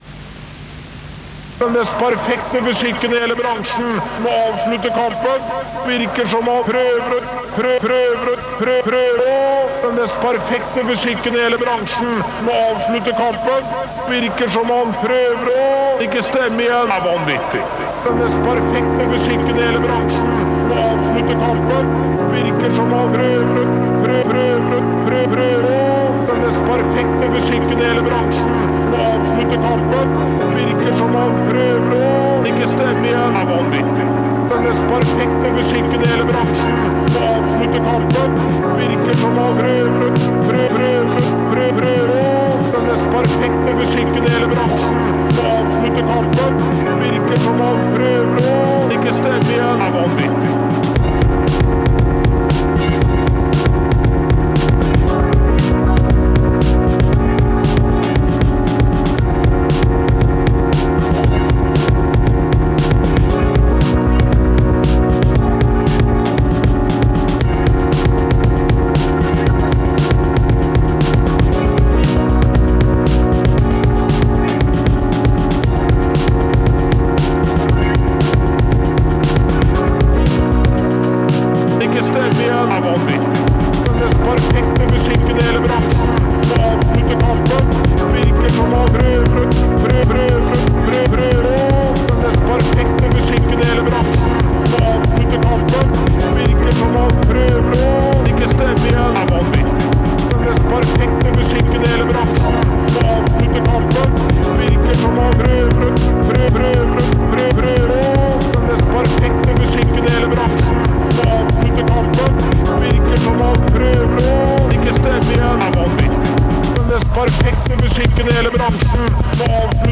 Directly recorded onto a cassette tape from the MPC... start & end edited in Ardour.